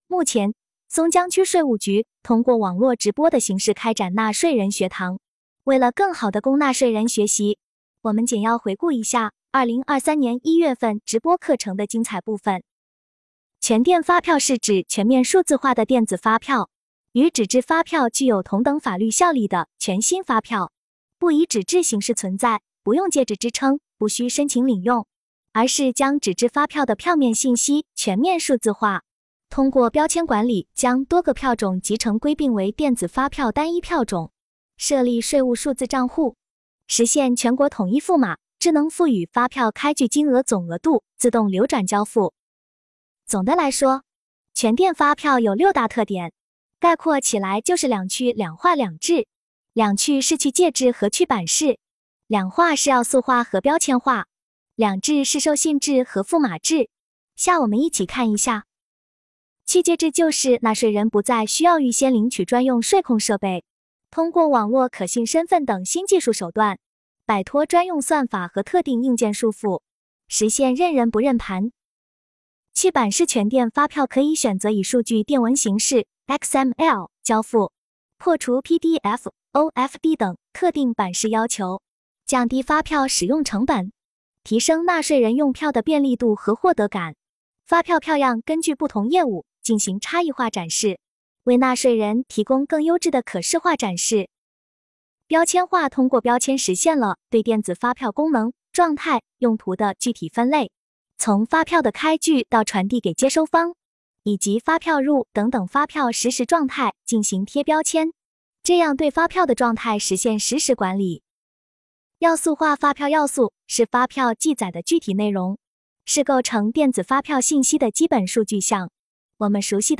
目前，松江区税务局通过网络直播的形式开展纳税人学堂。
直播课程一